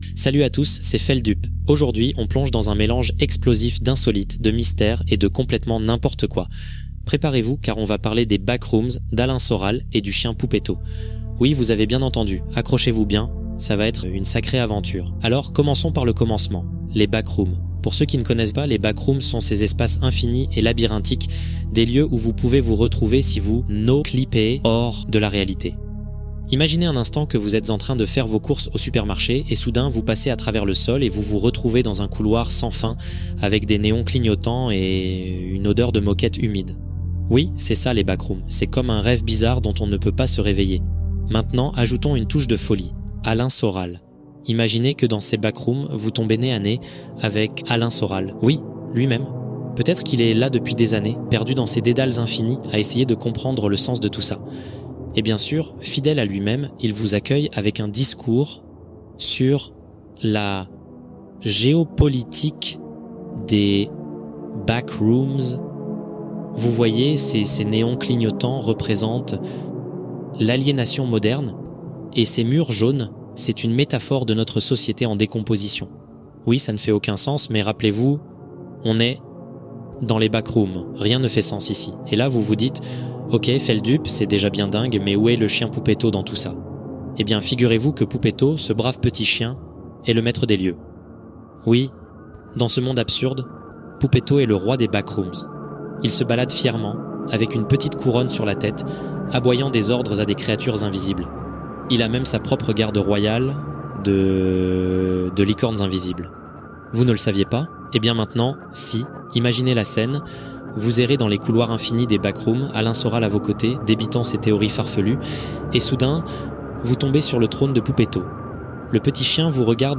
Vocaroo parodique où Feldup raconte une rencontre avec Alain Soral et Poupeto dans les Backrooms